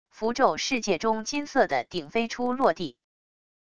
符咒世界中金色的鼎飞出落地wav音频